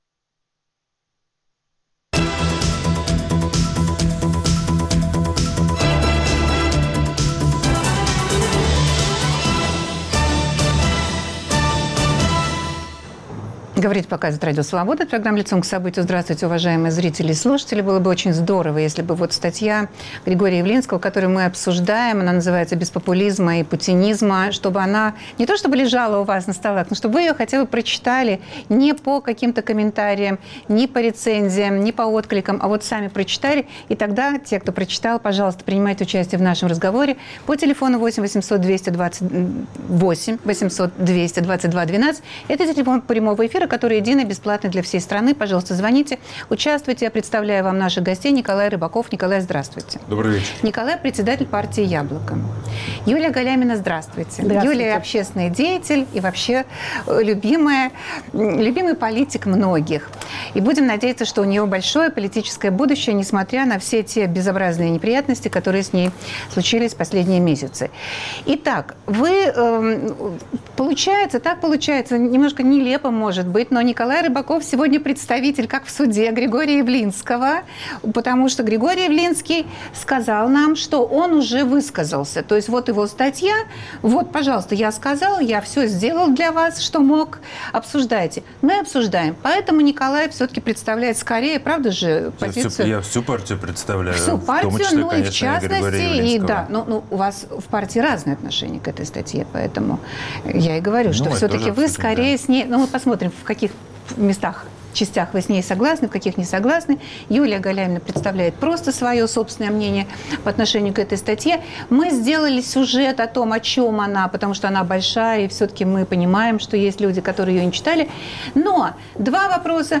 В дискуссии об этой статье участвуют: председатель партии "Яблоко" Николай Рыбаков и депутат, общественный деятель Юлия Галямина.